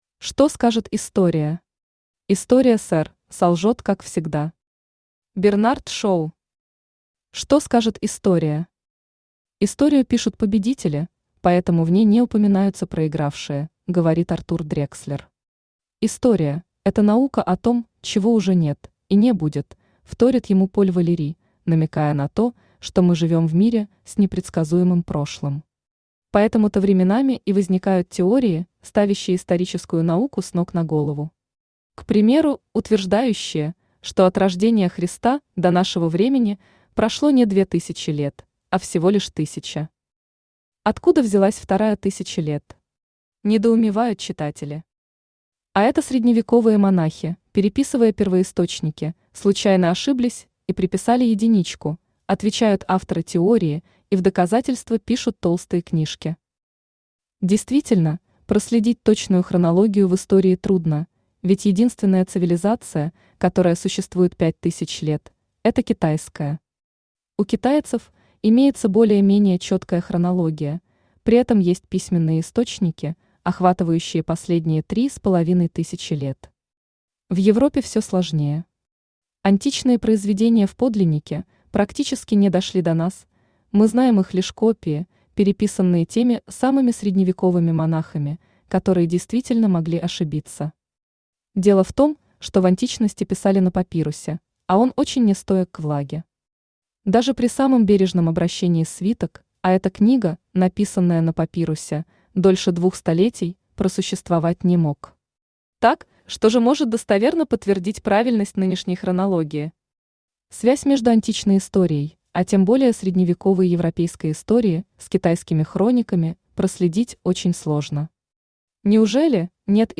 Аудиокнига Последняя ошибка императора | Библиотека аудиокниг
Aудиокнига Последняя ошибка императора Автор Александр Макаров Читает аудиокнигу Авточтец ЛитРес.